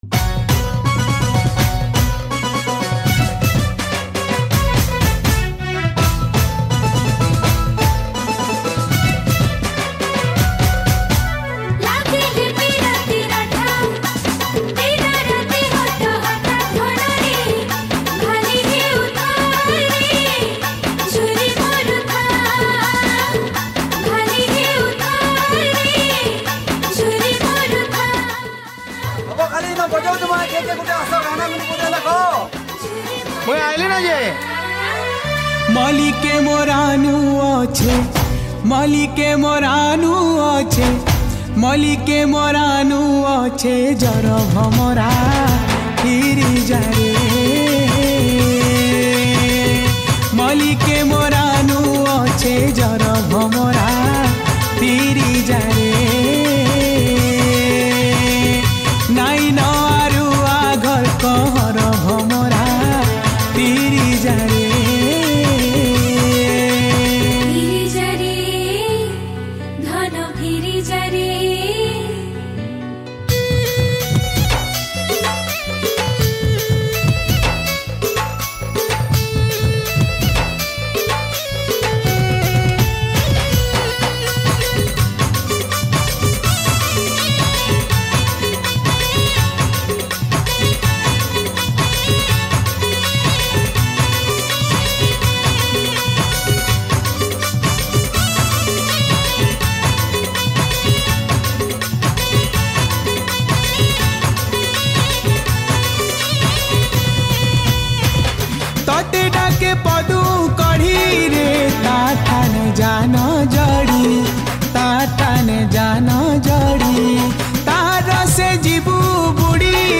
CHORUS
RR studio